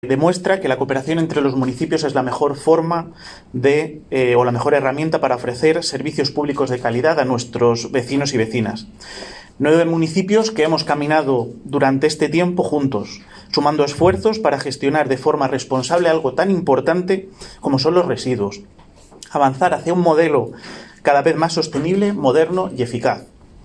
Declaraciones del alcalde, Miguel Óscar Aparicio Declaraciones del presidente de la MVH, Rafael Esteban
El alcalde ha destacado la importancia de la Mancomunidad Vega del Henares en la inauguración de su nueva oficina en Azuqueca